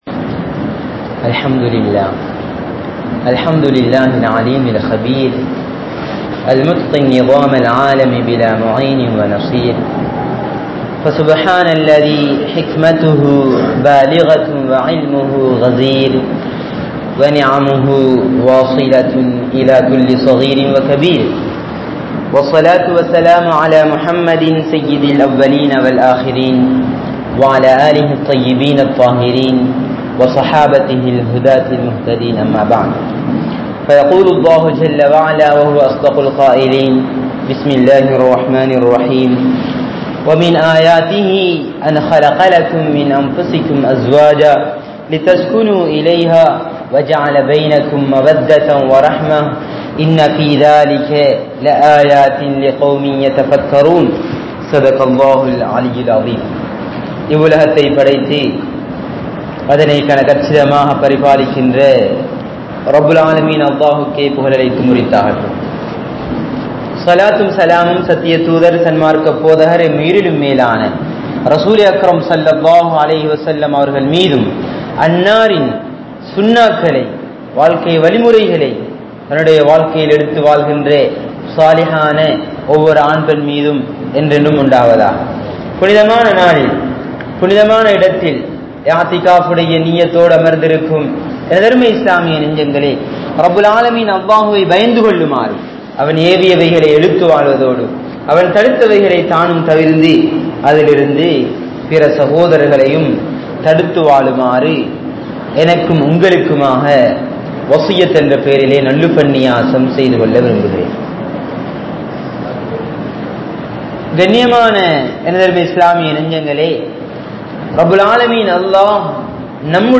Muslimkalidaththil Athihariththu Sellum Thalaq (முஸ்லிம்களிடத்தில் அதிகரித்து செல்லும் தலாக்) | Audio Bayans | All Ceylon Muslim Youth Community | Addalaichenai